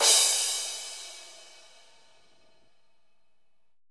PLAINCRSH.wav